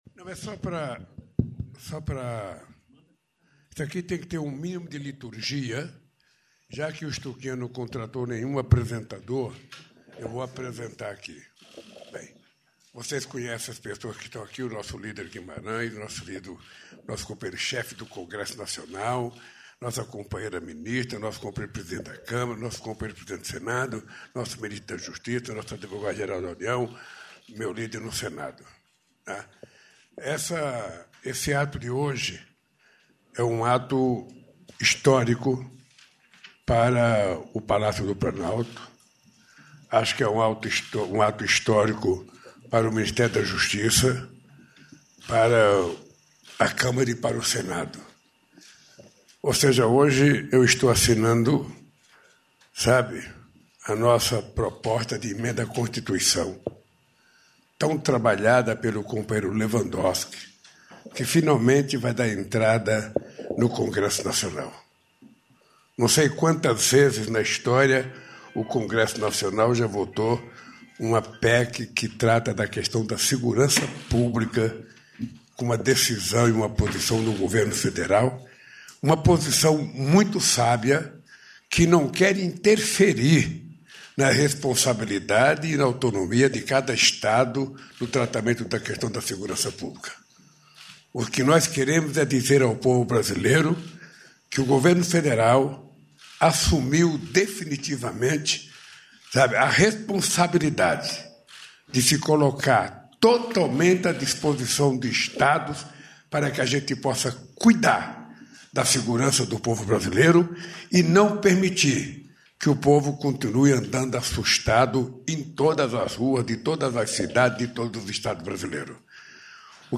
Íntegra do discurso do presidente da República, Luiz Inácio Lula da Silva, no evento onde assinou contrato para a aquisição de quatro navios da classe Handy, nesta segunda-feira (24), no no Estaleiro Rio Grande, no Rio Grande do Sul .